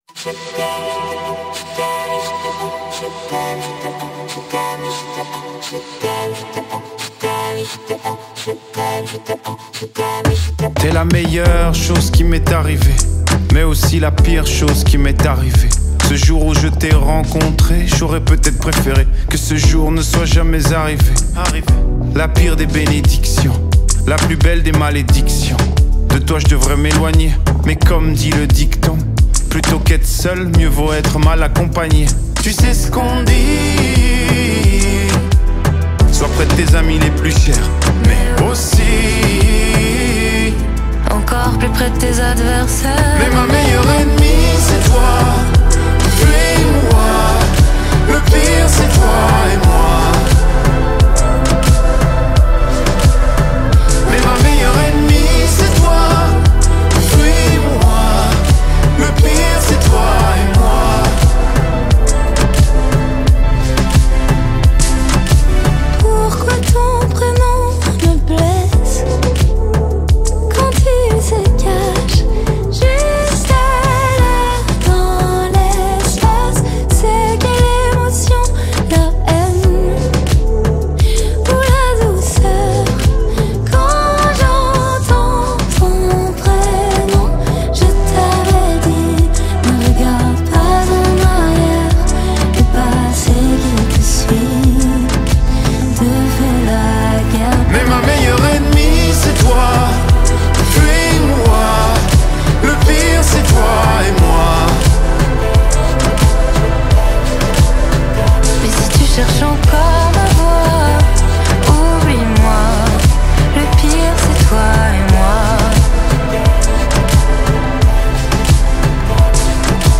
پاپ و الکتریک پاپ